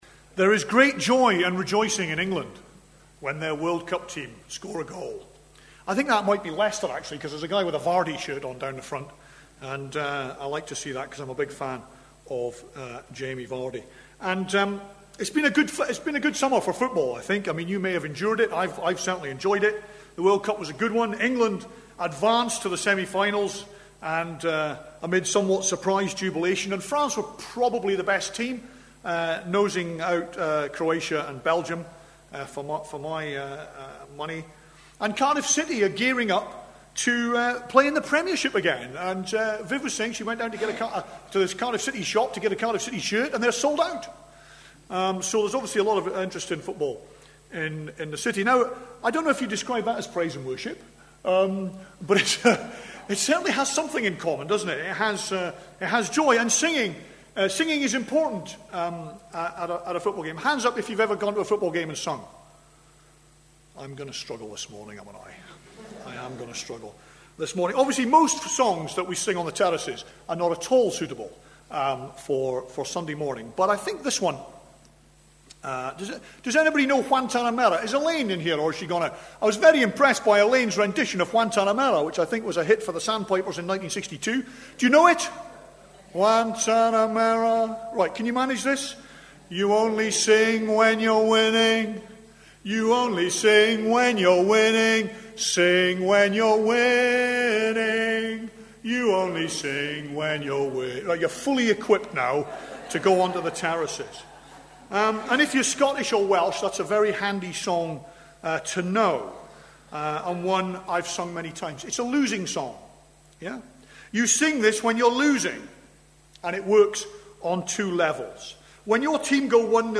Lifting Up Service Type: Sunday Morning Preacher